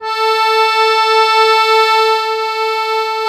MUSETTE1.8SW.wav